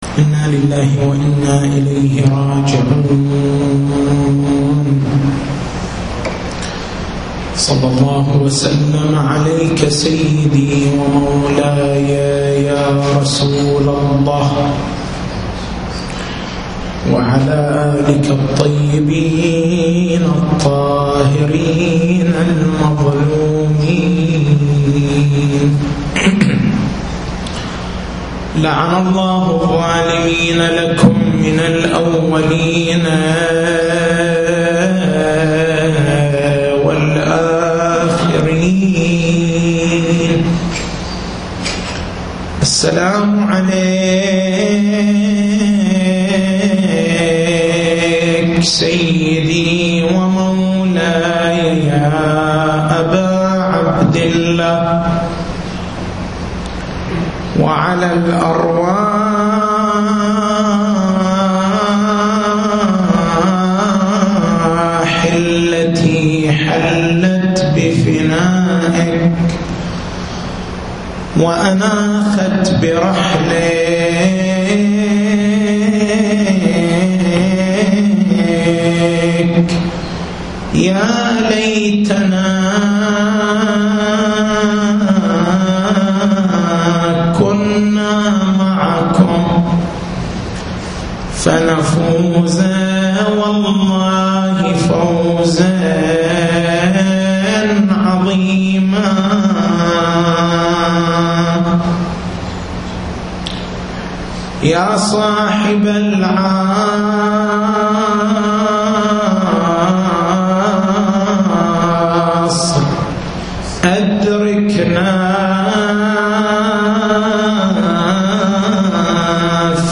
تاريخ المحاضرة: 14/09/1430